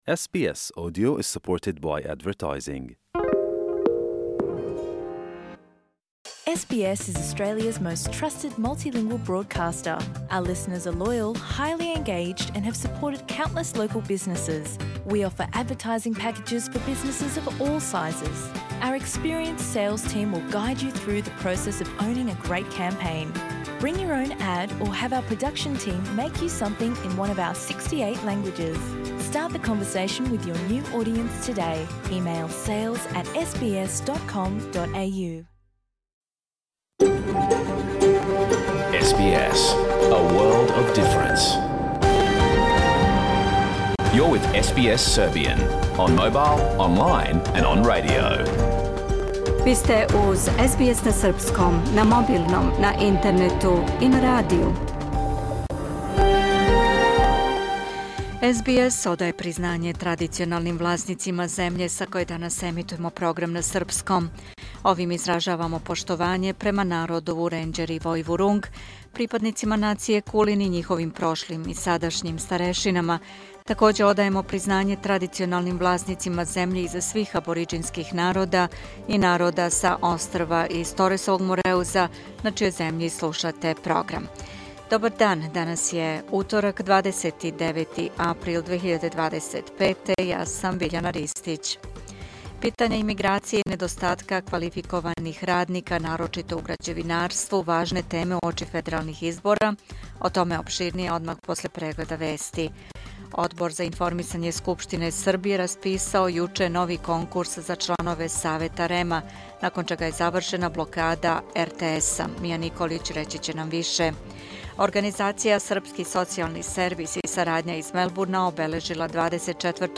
Програм емитован уживо 29. априла 2025. године
Уколико сте пропустили данашњу емисију, можете је послушати у целини као подкаст, без реклама.